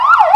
siren2.wav